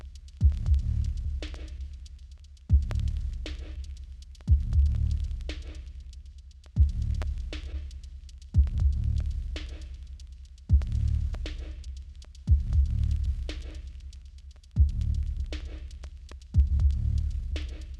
Trying to find the Trax Chicago Sound
glued